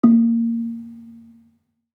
Gambang-A2-f.wav